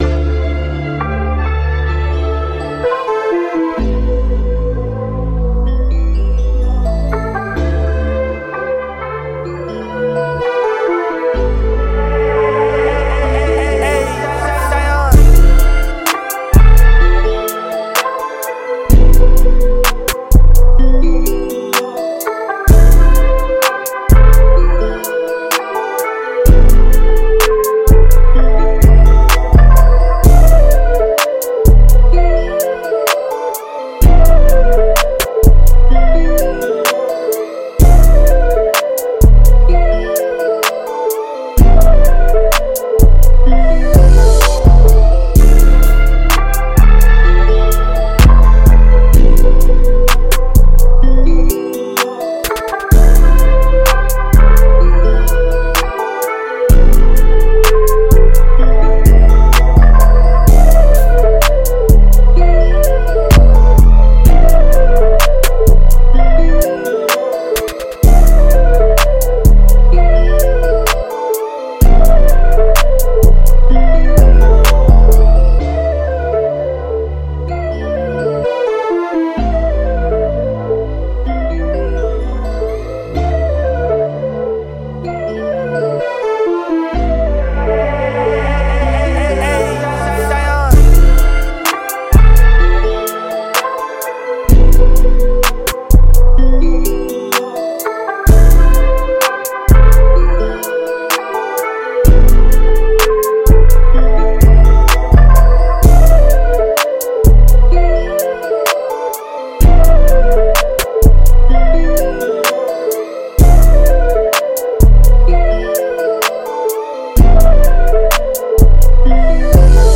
127 A Minor